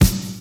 • 80s Round Punk Snare Drum Sound C Key 64.wav
Royality free steel snare drum sample tuned to the C note. Loudest frequency: 1917Hz
80s-round-punk-snare-drum-sound-c-key-64-RPK.wav